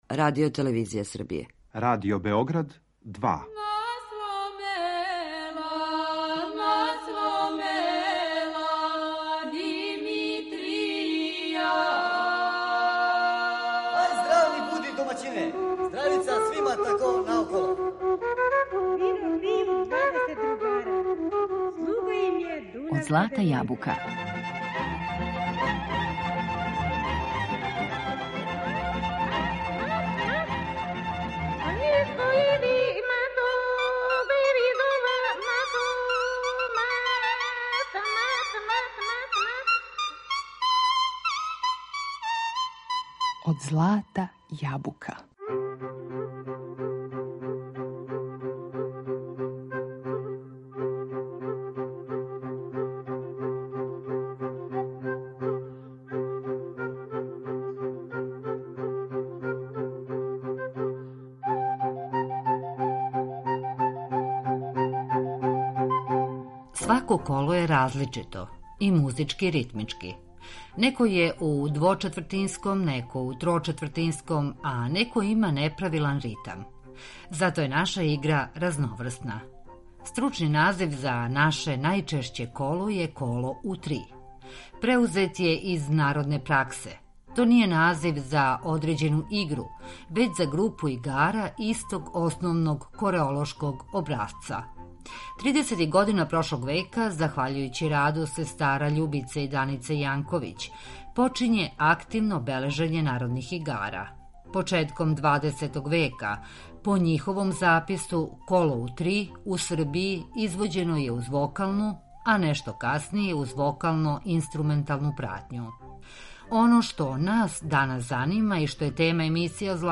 За ову прилику, у данашњој емисији Од злата јабука, пробраћемо десетак српских песама које су некада певане у колу.